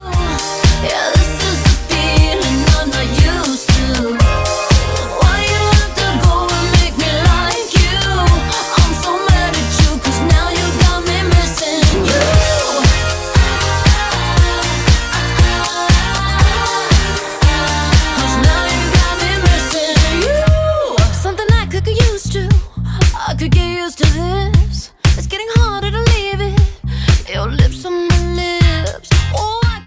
• Pop
A pop and disco recording
with guitars and digital harps over an uptempo melody.